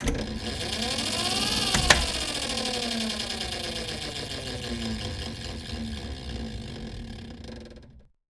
TransportAccessTube.wav